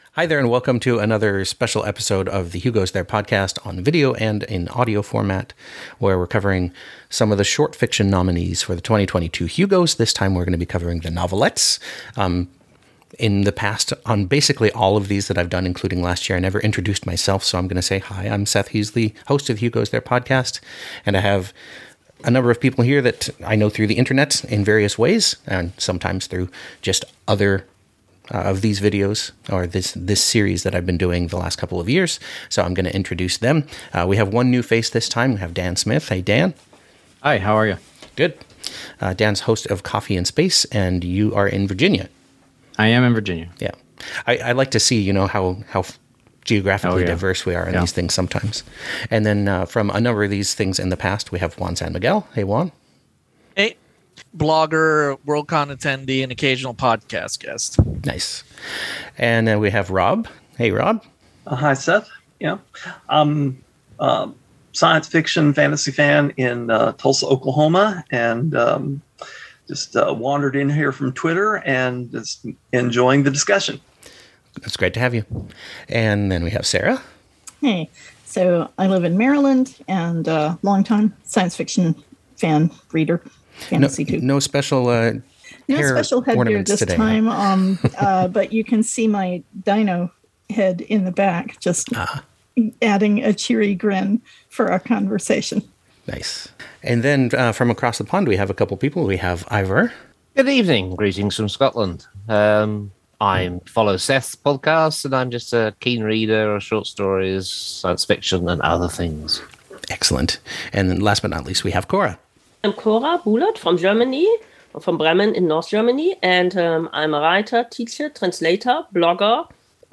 This is the audio-only version of a Zoom discussion panel about the 2022 Hugo nominees for Best Novelette.
2022-hugo-nominees-for-novelette-discussion-panel.mp3